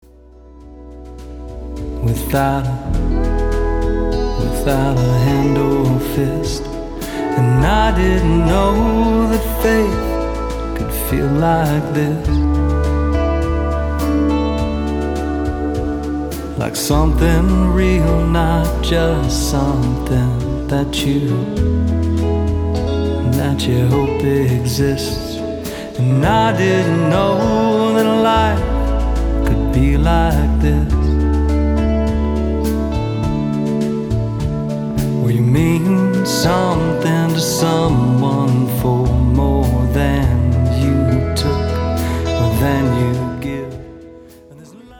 Country, Jewish, Americana